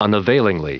Prononciation du mot unavailingly en anglais (fichier audio)
Prononciation du mot : unavailingly